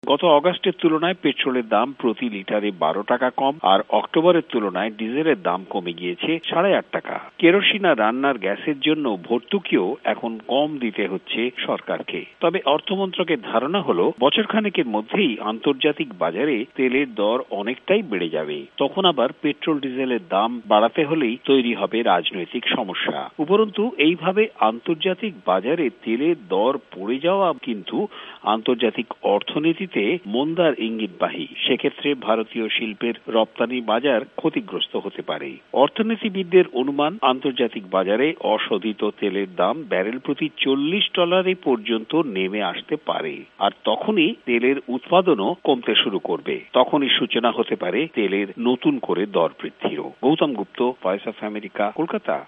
ভয়েস অব আমেরিকার কলকাতা সংবাদদাতাদের রিপোর্ট